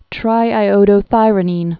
(trīī-ōdō-thīrə-nēn, -ī-ŏdō-)